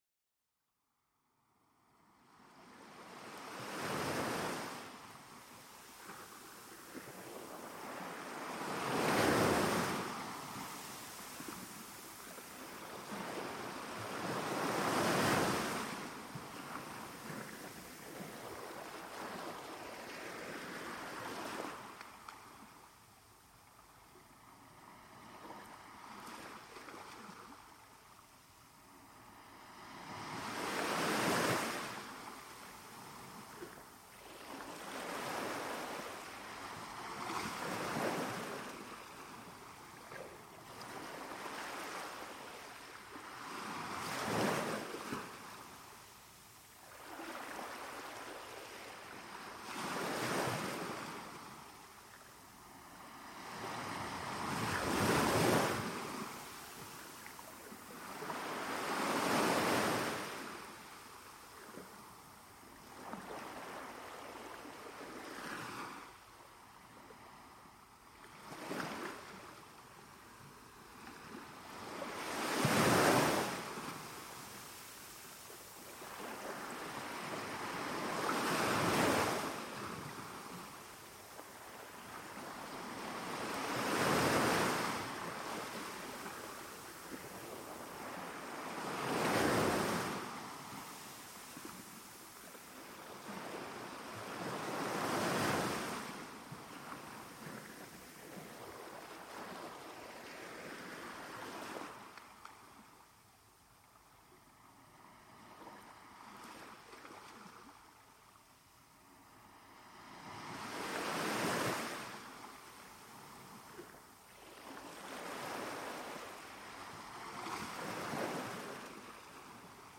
Olas Relajantes: Relajación y Sueño al Sonido del Océano